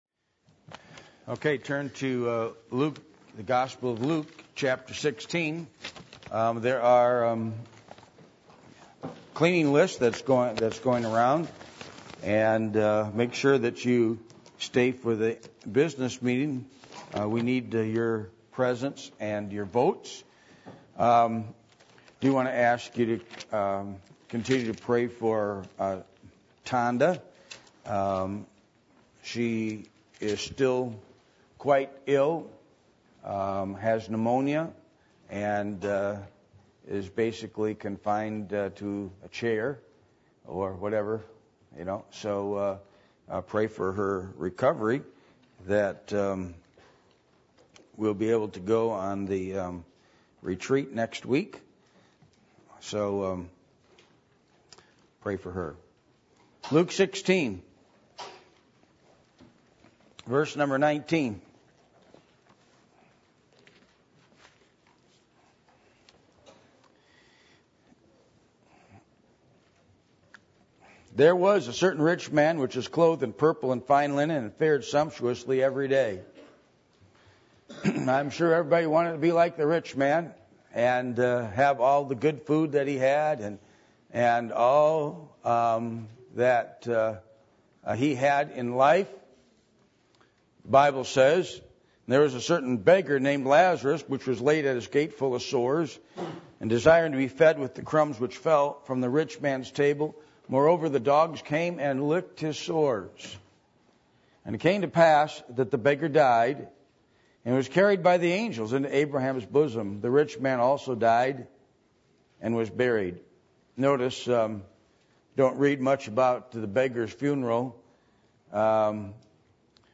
Passage: Luke 16:19-31 Service Type: Midweek Meeting